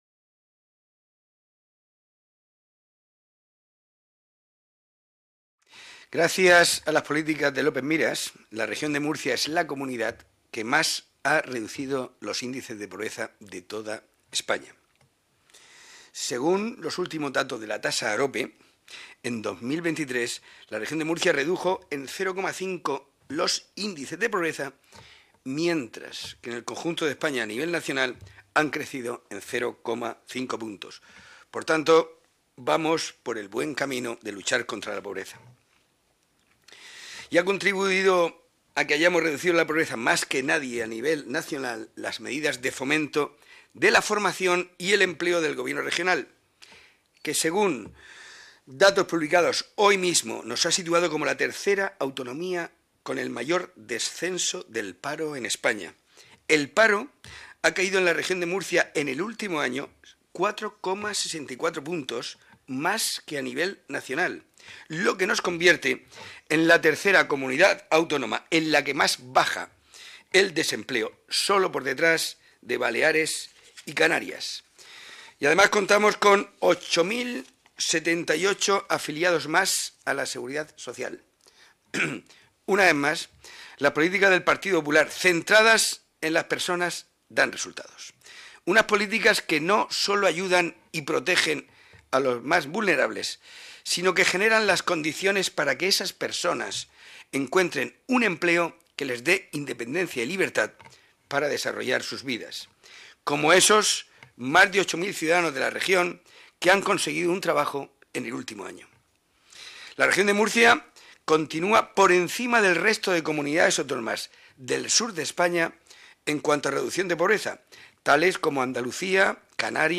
Rueda de prensa tras la Comisión Especial de Estudio de Lucha contra la Pobreza y la Exclusión Social en la Región de Murcia
• Grupo Parlamentario Popular